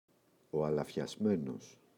αλαφιασμένος [alafçaꞋzmenos] – ΔΠΗ